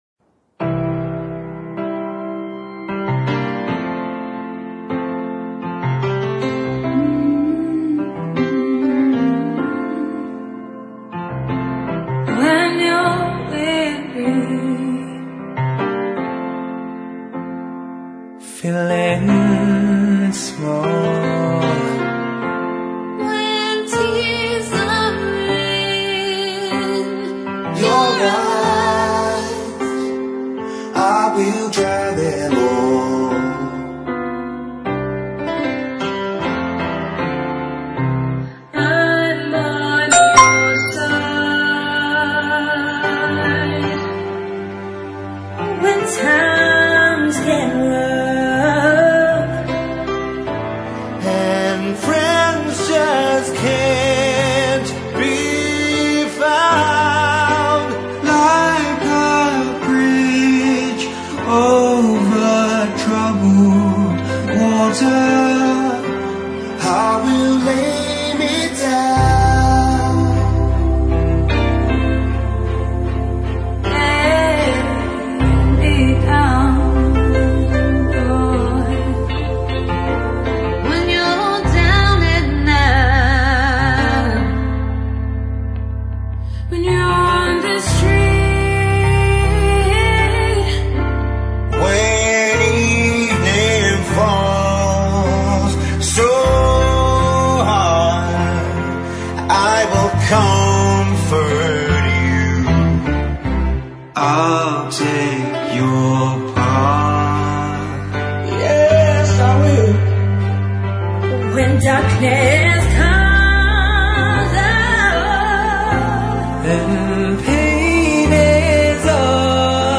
Blues Jazz